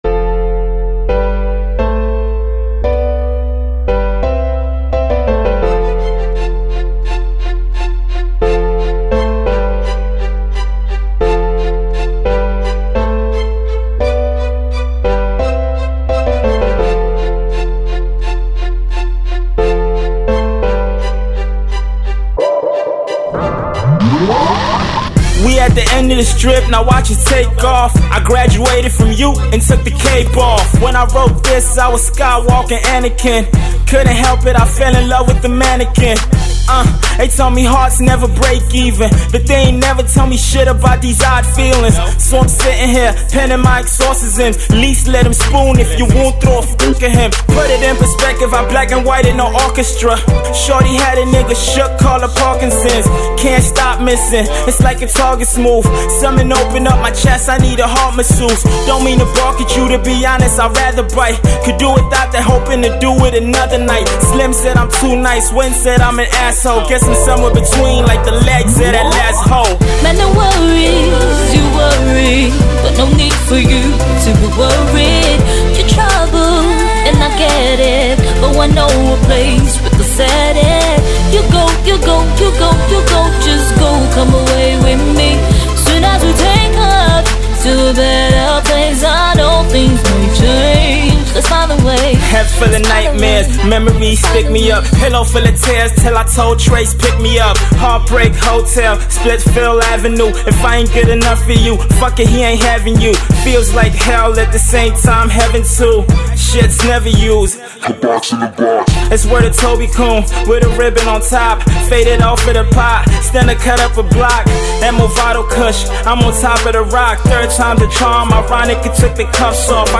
Hip-Hop
good wordplay and entertaining punch lines